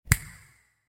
snap.ogg.mp3